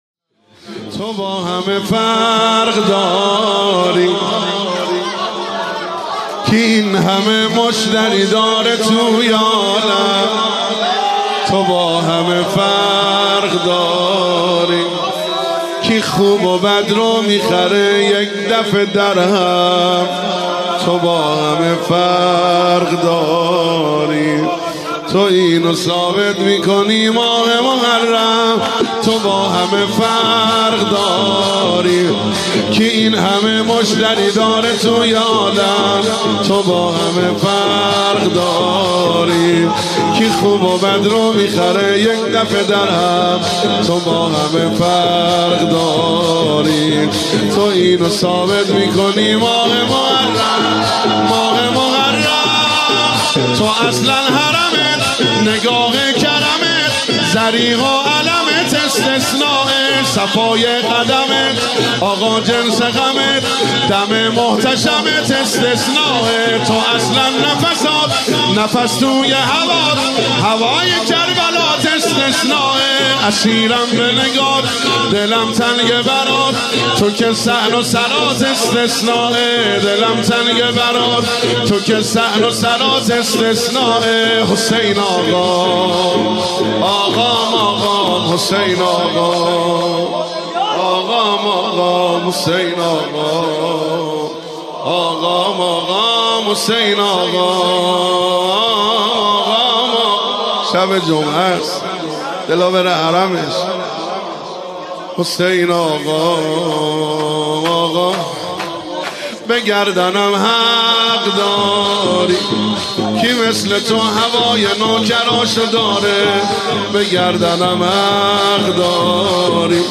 مداحی و نوحه
مداحی فاطمیه ۱۳۹۶
(شور)